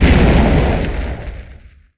fireimpact.wav